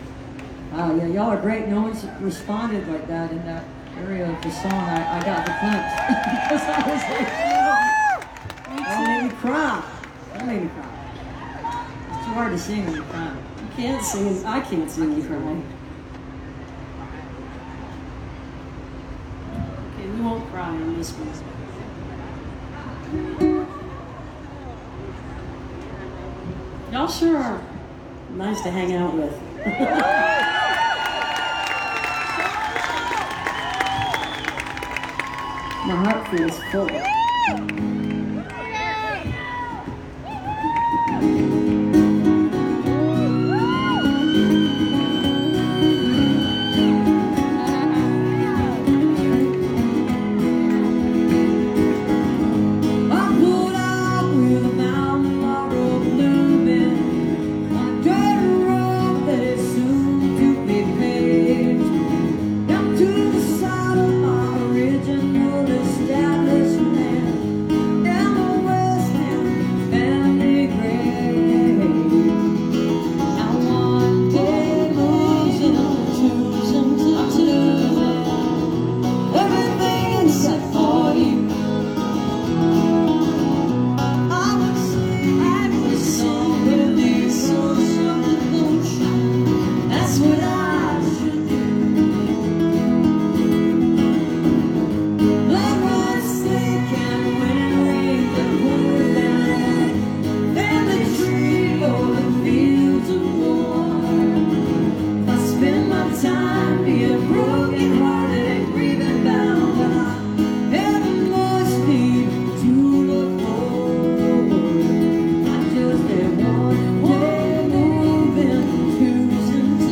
(captured from the facebook livestream)